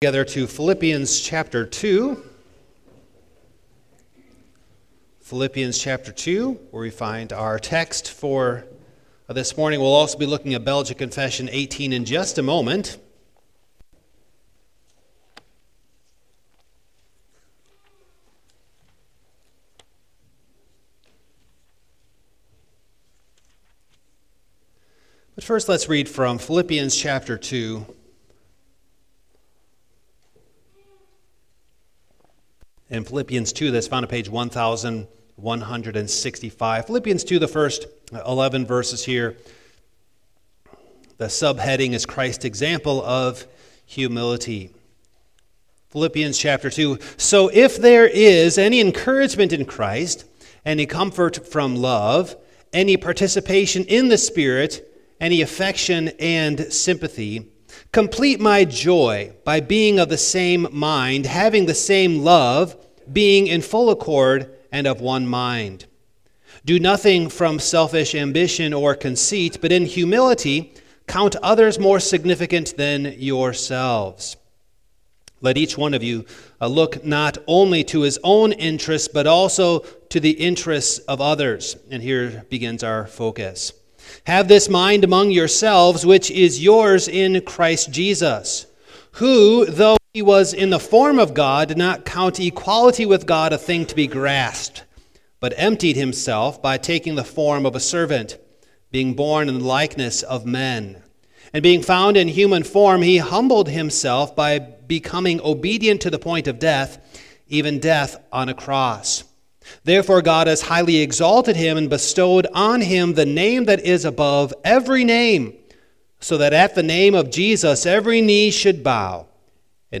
Passage: Phil. 2: 1-10 Service Type: Morning